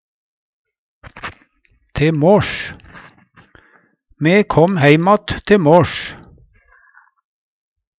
te måsj - Numedalsmål (en-US)